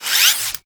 Sfx_tool_spypenguin_deploy_cam_01.ogg